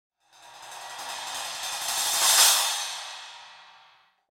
Metallic Crash Transition & Impact Sound Effect
This audio delivers a sharp, high-impact metallic strike that instantly grabs attention, followed by a long, shimmering reverb that smoothly fades into silence. The powerful cymbal-like hit creates dramatic emphasis, making it ideal for transitions, accents, and impactful moments in film, trailers, and sound design.
Metallic-crash-transition-impact-sound-effect.mp3